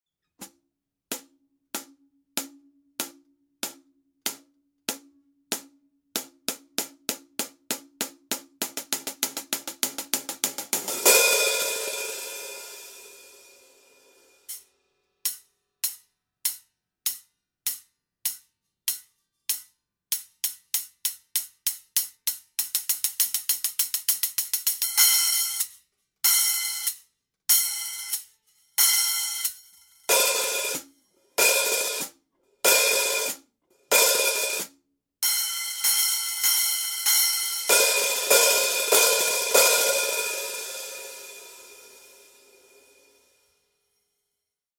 Here’s how the Praxis cymbals sound recorded side by side with our Heartbeat Classic series cymbals:
Compare Heartbeat Classic 14″ hi-hats to Praxis 14″ Hi-hats:
14__Classic-Hats-_-Practice-Hats.mp3